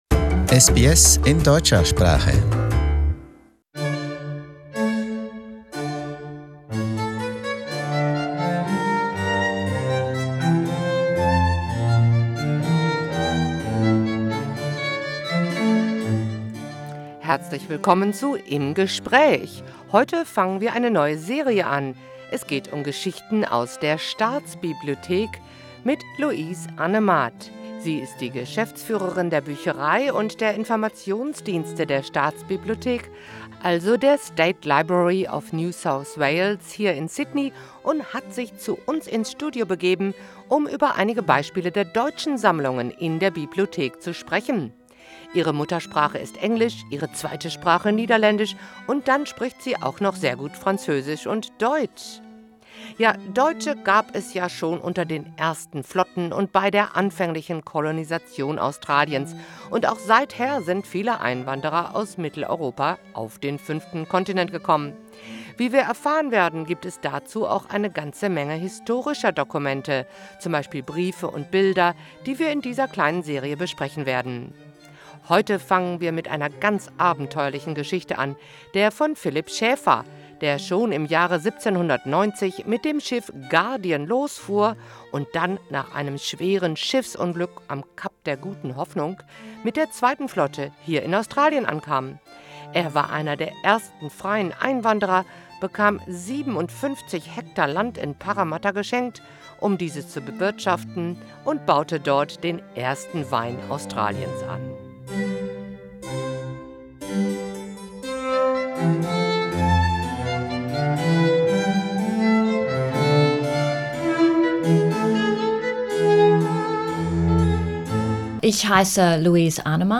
Sie kam zu uns ins Studio, um sich über einige Beispiele deutscher Sammlungen in der Bibliothek zu unterhalten. In dieser Folge beginnen wir mit der abenteuerlichen Geschichte von Philip Schaeffer, der 1790 mit dem Schiff „Guardian“ losfuhr und schließlich ein Jahr später, nach einem verheerenden Schiffbruch am Kap der Guten Hoffnung, mit der zweiten Flotte ankam. Er war einer der ersten freien Einwanderer in die neue Kolonie und erhielt 57 Hektar Land in Parramatta, wo er das erste Weingut in Australien aufbaute.